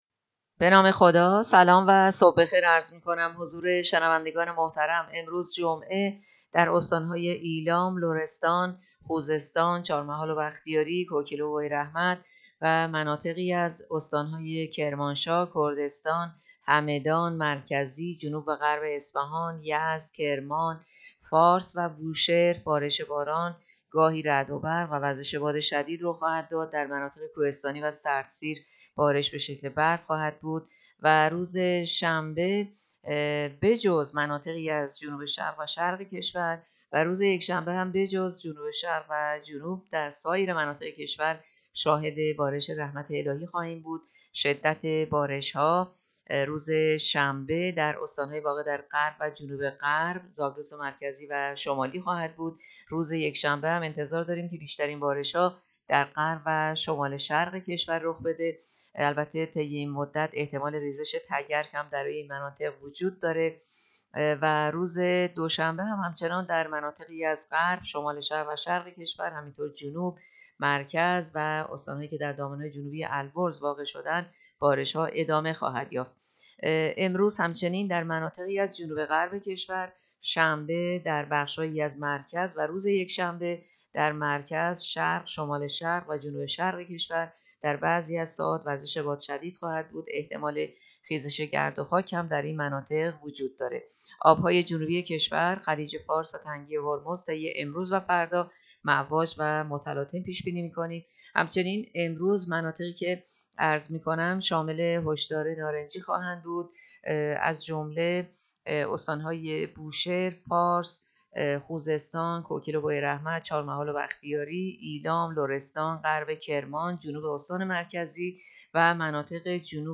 گزارش رادیو اینترنتی پایگاه‌ خبری از آخرین وضعیت آب‌وهوای۱۷ اسفند؛